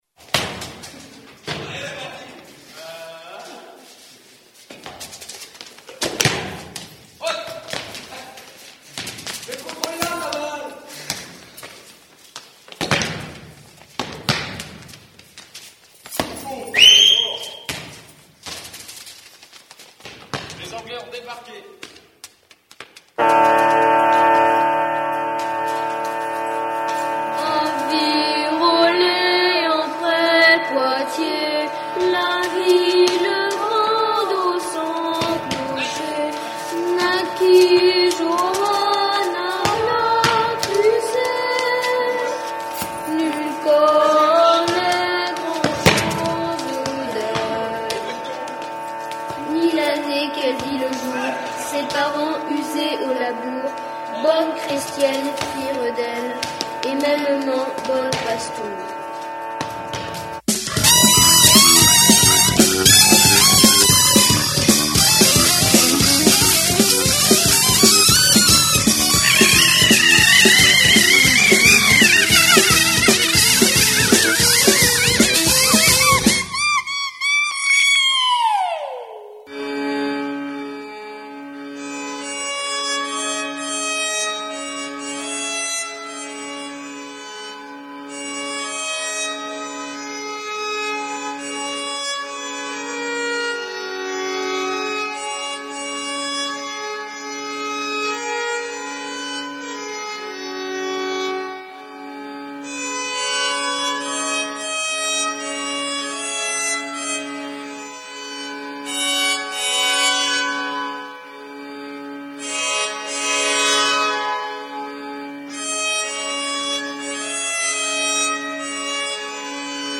medieval fiddle